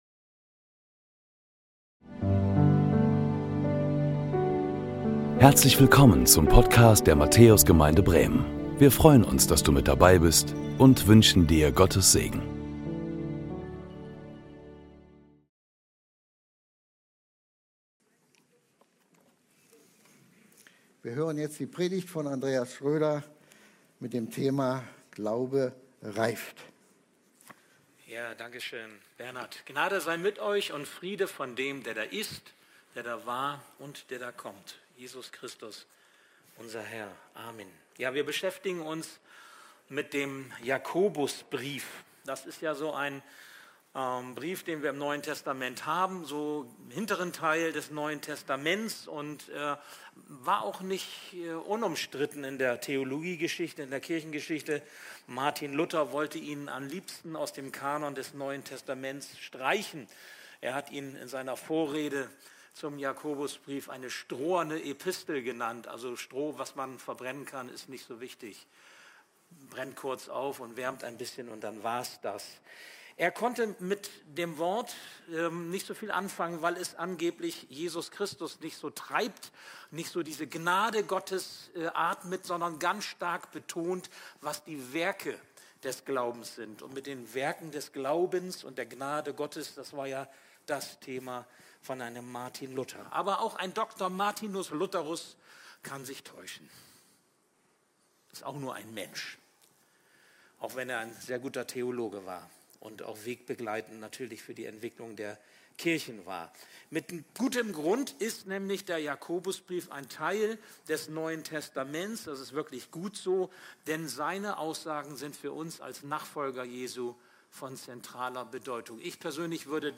Predigten der Matthäus Gemeinde Bremen